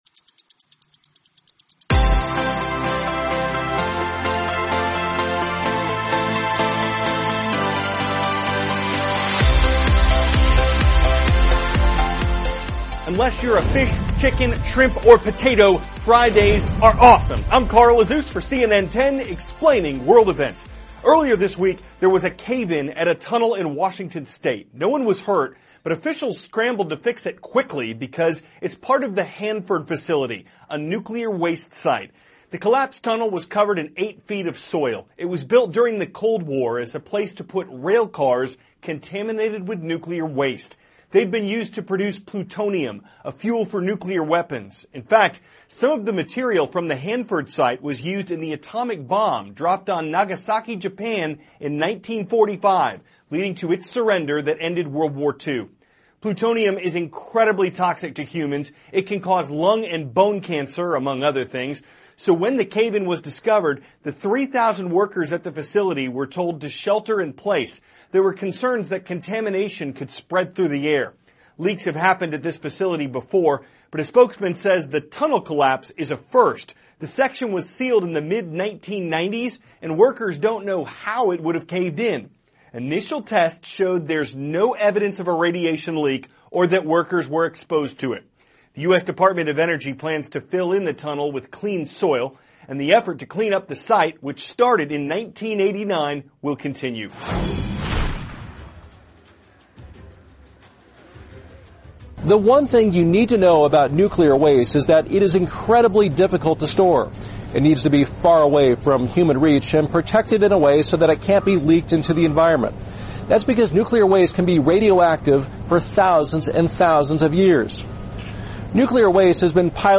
(cnn Student News) -- May 12, 2017 A tunnel Collapse At A Nuclear Waste Site; Media Perspectives from North Korea; Tennis Empowers A Florida Woman With Autism THIS IS A RUSH TRANSCRIPT.